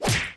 Download slap x
slap